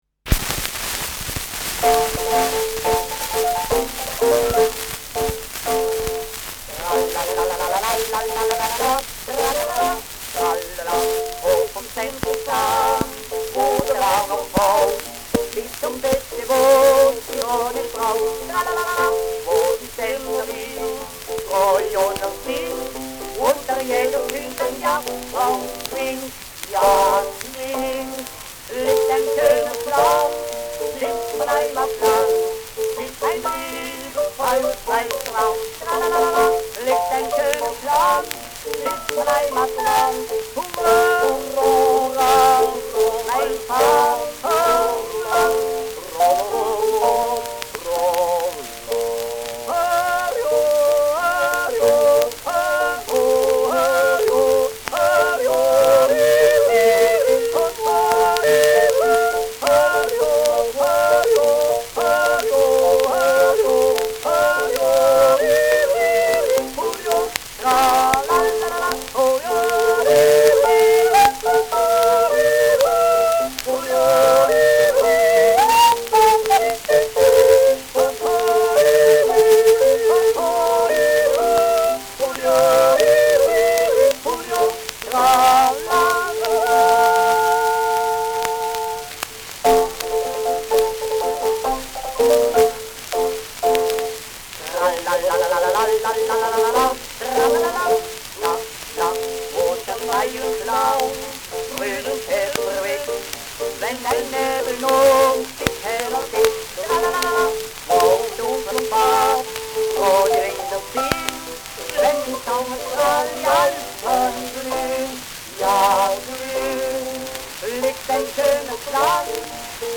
Schellackplatte
präsentes Rauschen : abgespielt : Knacken zu Beginn : leichtes bis präsentes Knistern : gelegentliches „Schnarren“ : gelegentliches Knacken
[unbekannte musikalische Begleitung] (Interpretation)
[Zürich] (Aufnahmeort)